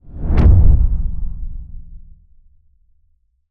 cinematic_deep_low_whoosh_impact_05.wav